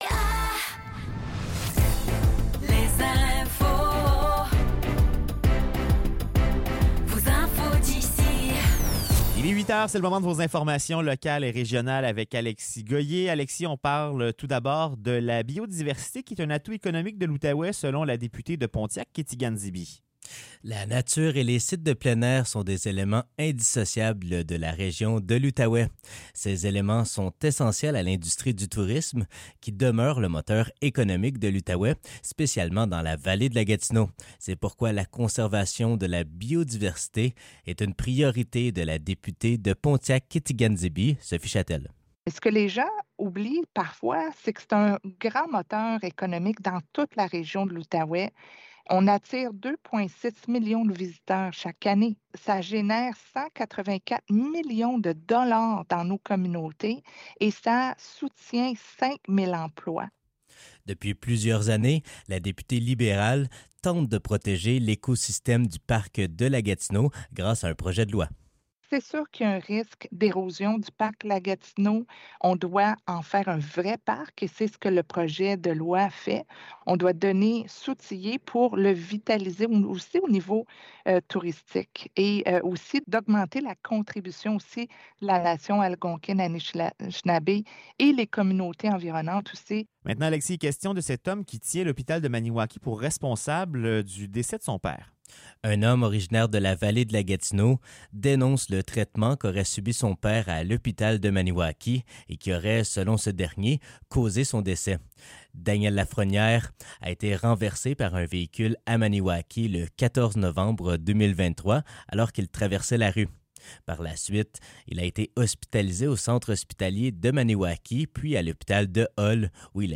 Nouvelles locales - 3 janvier 2025 - 8 h